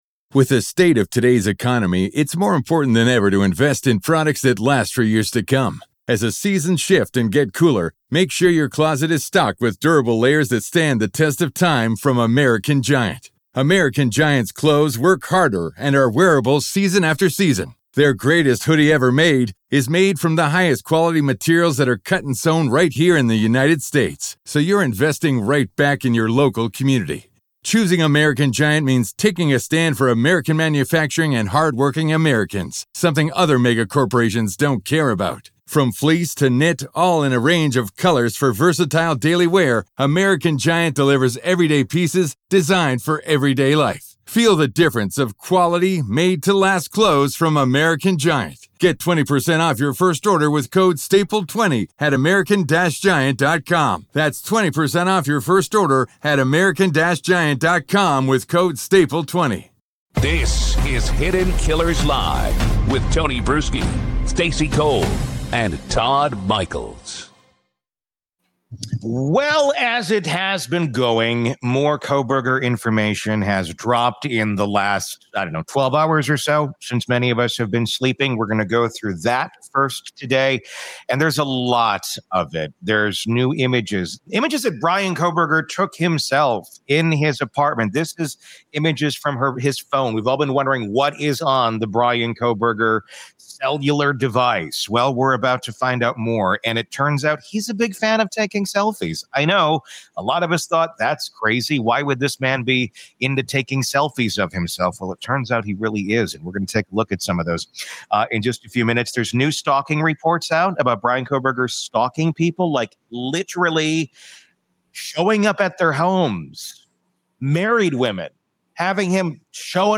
This segment mixes sharp banter with chilling analysis — a perfect entry point into the disturbing world of Kohberger’s phone.